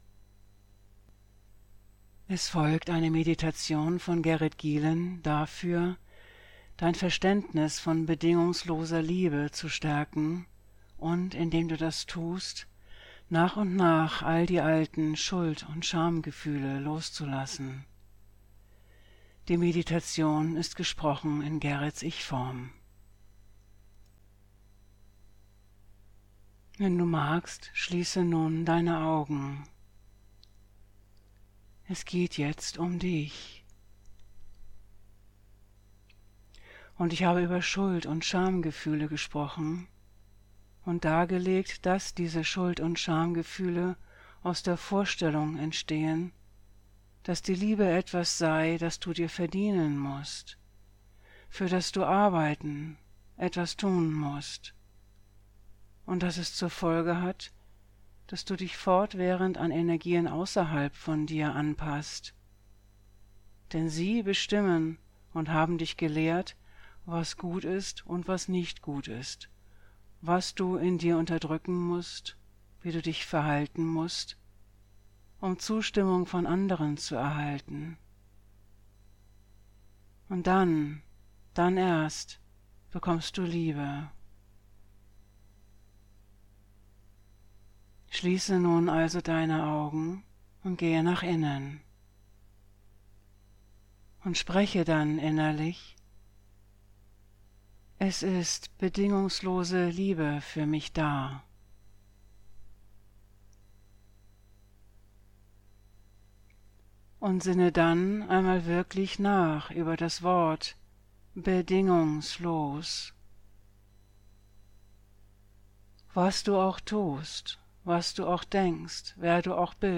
MEDITATION Die nun folgende Meditation ist eine Anleitung dafür, in Kontakt mit deinem wahren Selbst zu kommen.